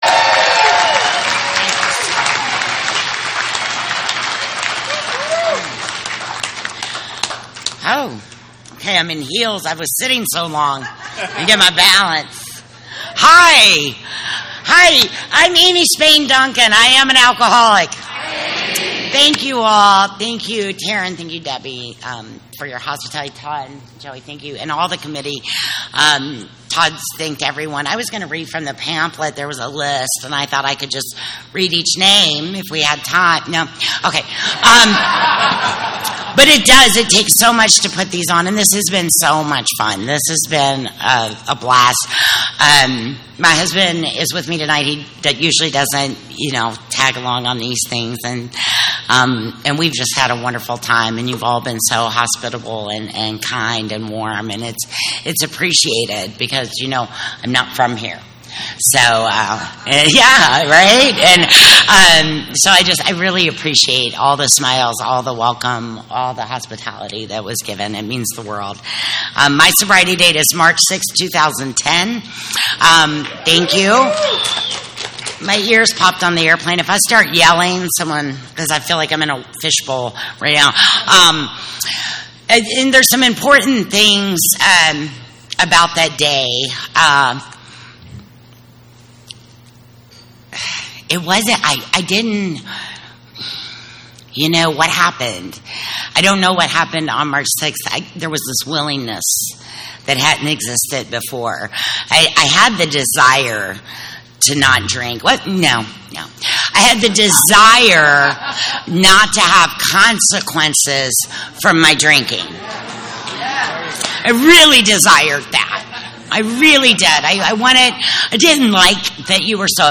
47th Annual San Fernando Valley AA Convention